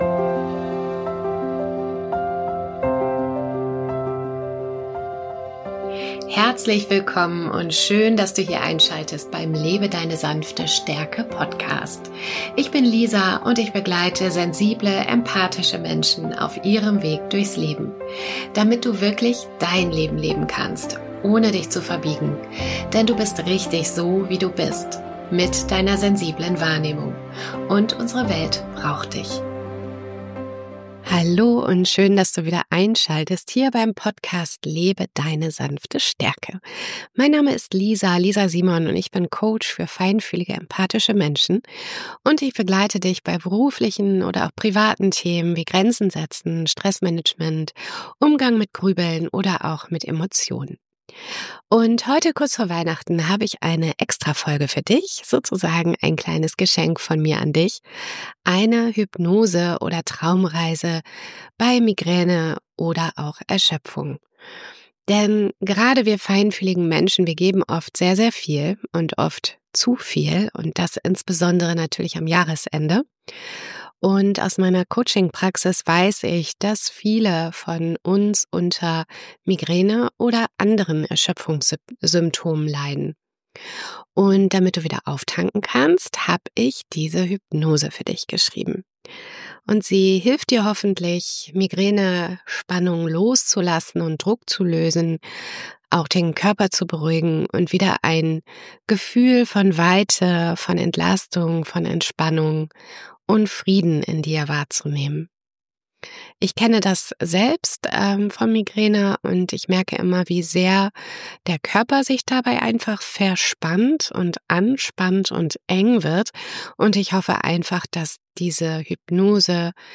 In dieser Folge hörst du eine sanfte Hypnose – eine beruhigende Traumreise, die dir bei Migräne, Erschöpfung und innerer Anspannung helfen kann. Durch Naturbilder und eine wohltuende Visualisierung findest du Schritt für Schritt zurück in tiefe Entspannung.